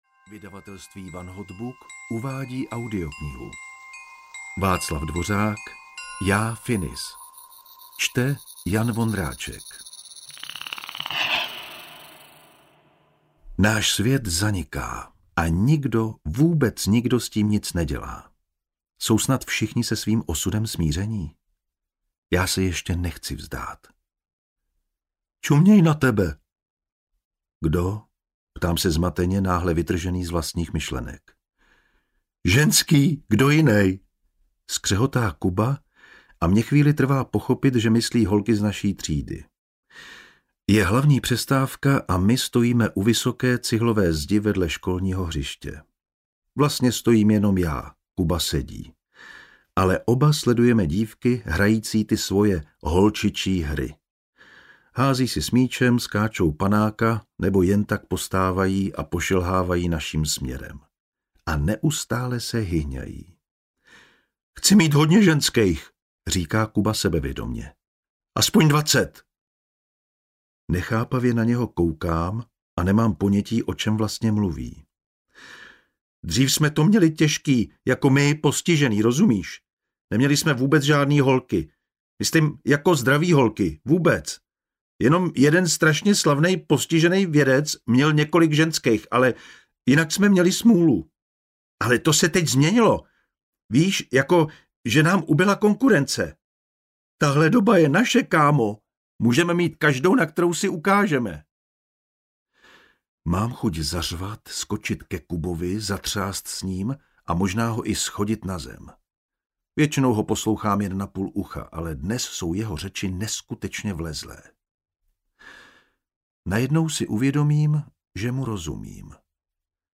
Já, Finis audiokniha
Ukázka z knihy
• InterpretJan Vondráček